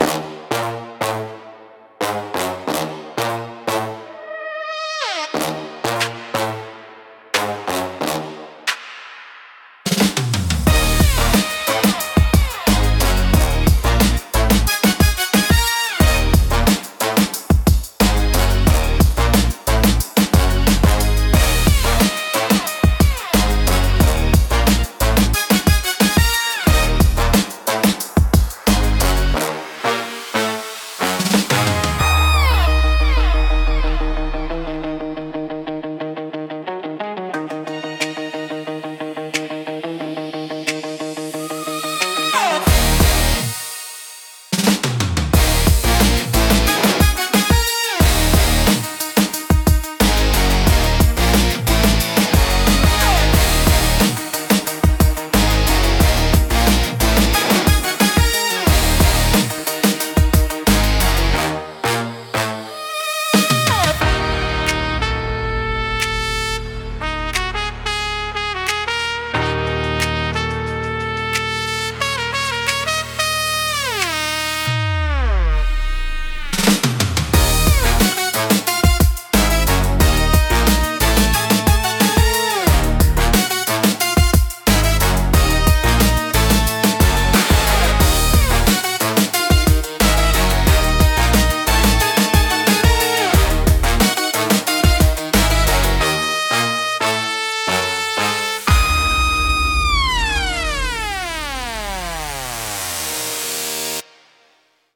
BGMセミオーダーシステムオリジナルのスパイは、迫力あるブラスセクションと怪しげなメロディが特徴のジャンルです。
緊張感とスリルを感じさせるリズムとサウンドが、危険な雰囲気や謎めいた場面を強調します。
不穏さとエネルギーが同居し、聴く人の集中力を高めつつドキドキ感を作り出します。